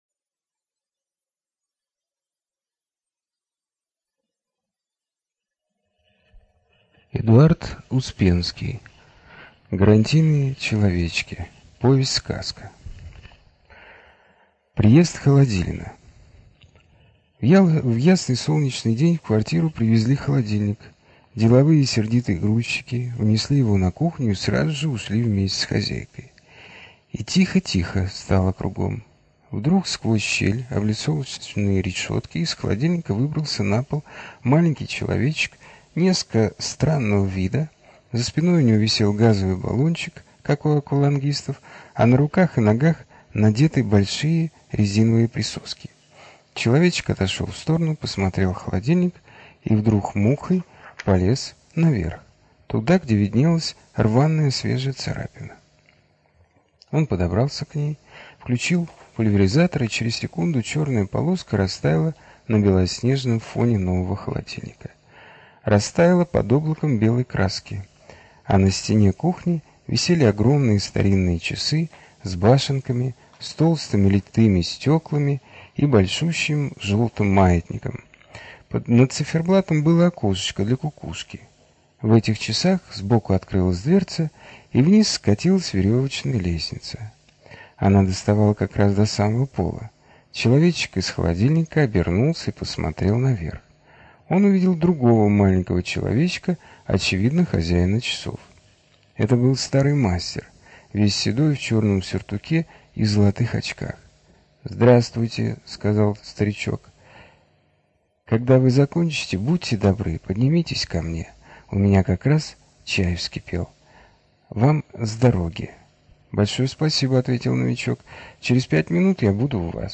ЖанрДетская литература